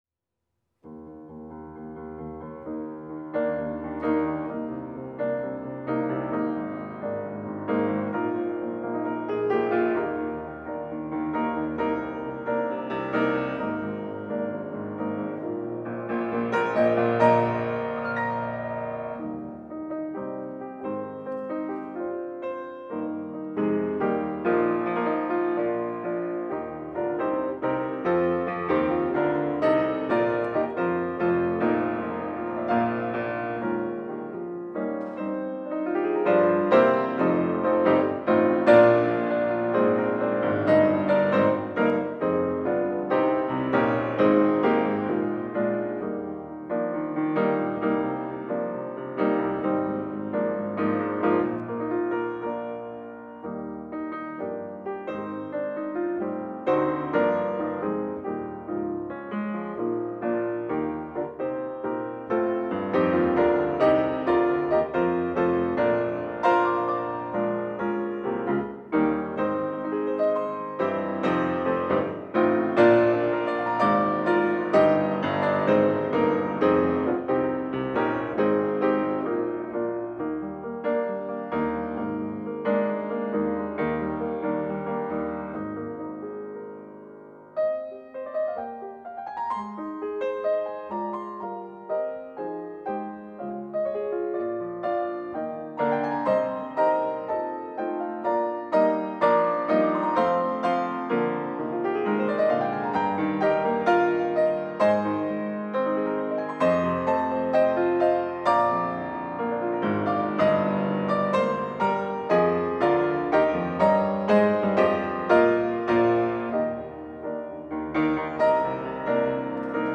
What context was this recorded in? Sanctuary-April-18-audio2.mp3